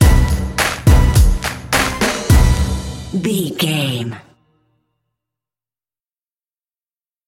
Aeolian/Minor
drum machine
synthesiser
funky
aggressive
hard hitting